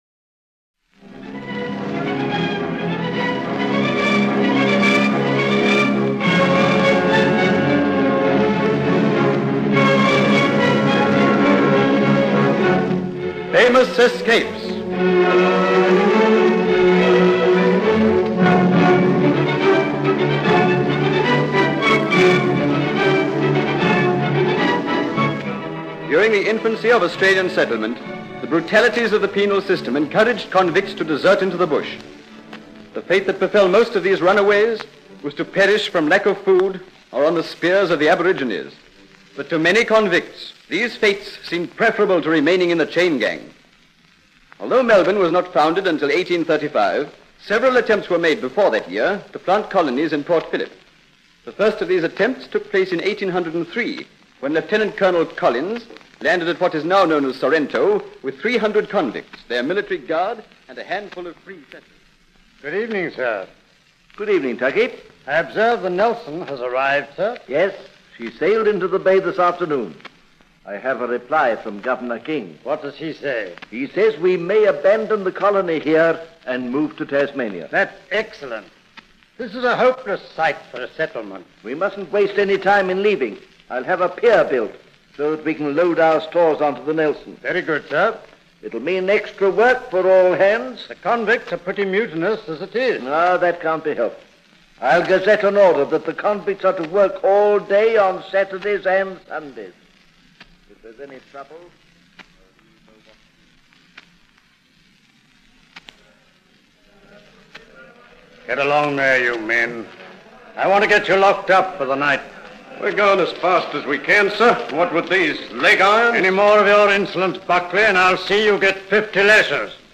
Famous Escapes was a captivating radio series produced in Australia around 1945. The show delved into some of the most daring escapes in history, featuring remarkable characters who managed to break free from seemingly impossible situations.